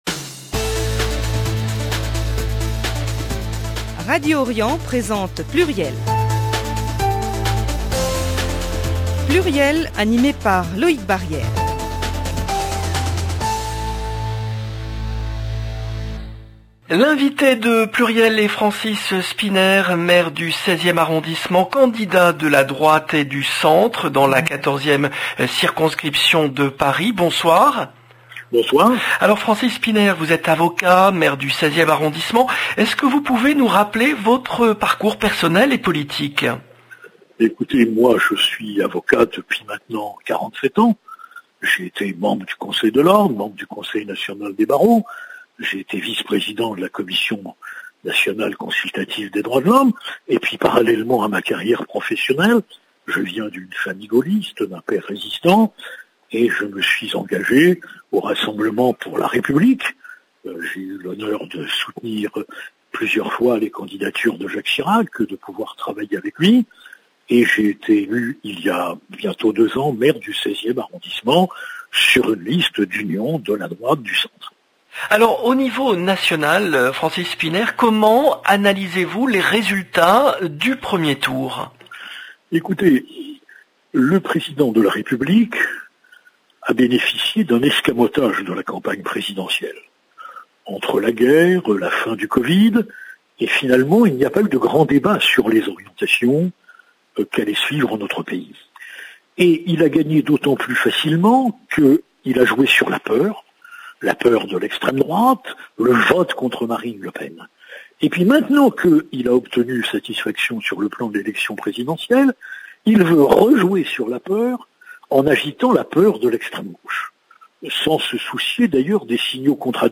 PLURIEL, le rendez-vous politique du vendredi 17 juin 2022